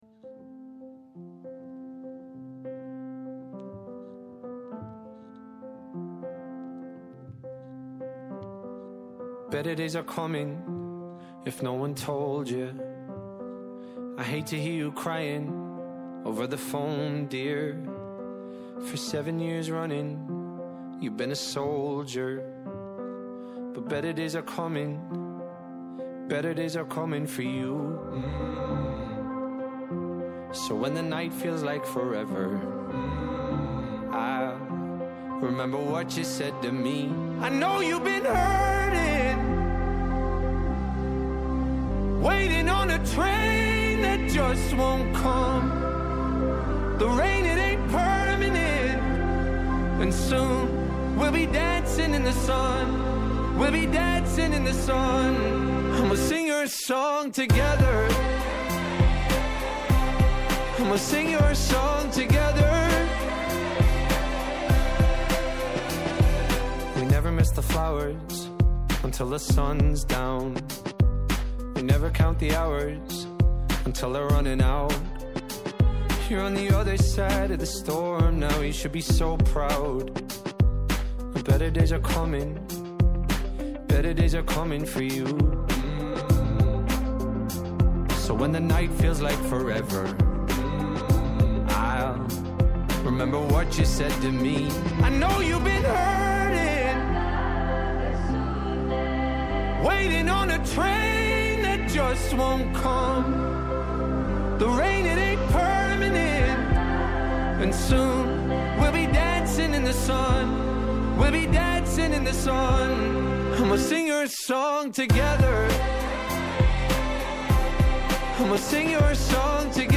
στο Πρώτο Πρόγραμμα της Ελληνικής Ραδιοφωνίας